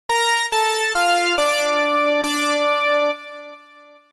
corinthiaaaaaaaaaaaaaaaaaans Meme Sound Effect
corinthiaaaaaaaaaaaaaaaaaans.mp3